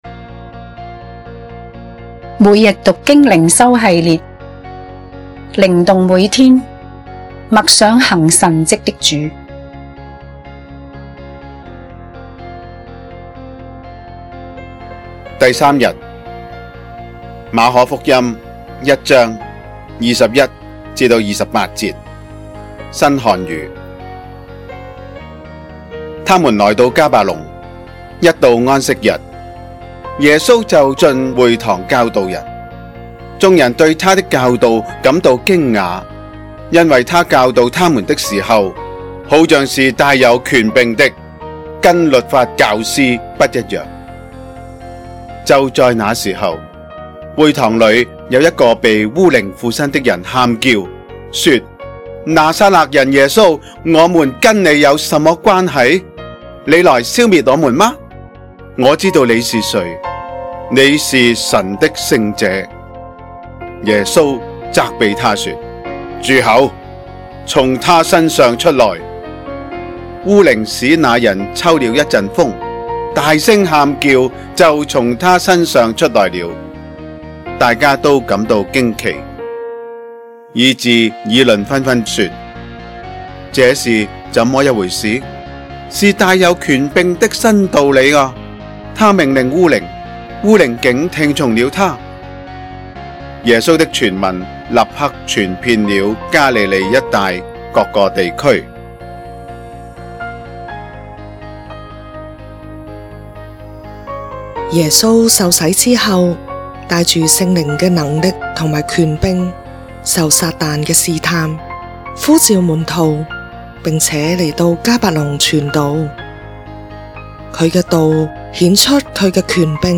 經文閱讀